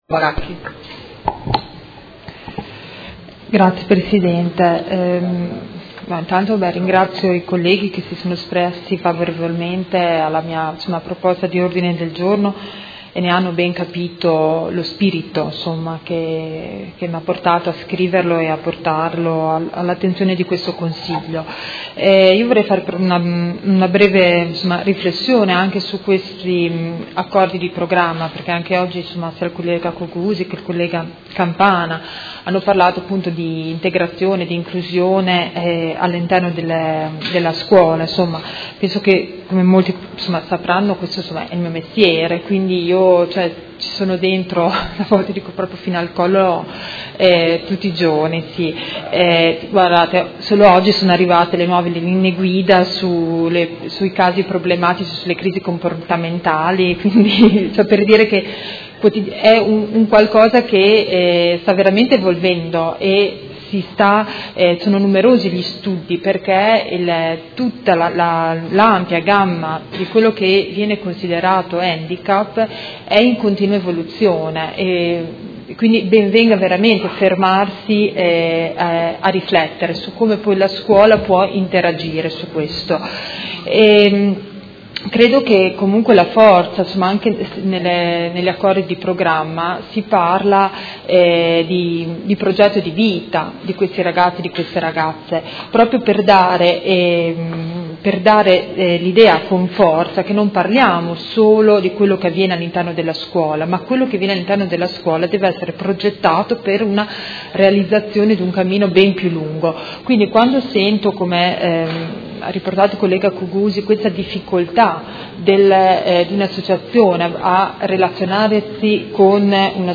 Seduta del 20/07/2017 Dibattito.